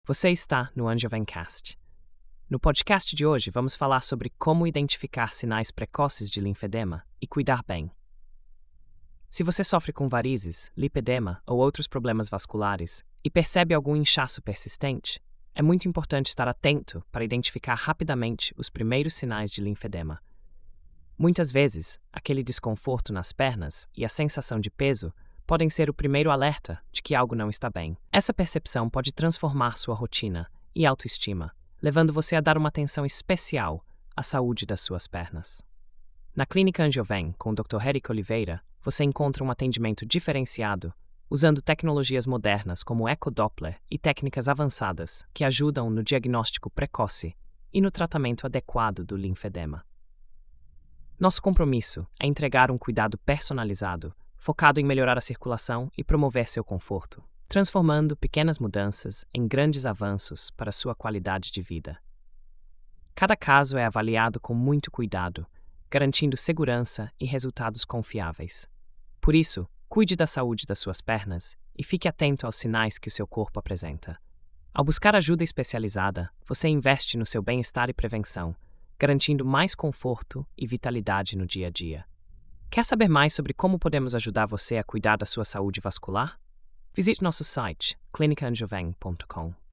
Narração automática por IA